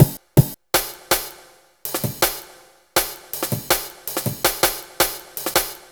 Dinky Break 02-162.wav